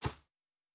Shoe Step Grass Hard B.wav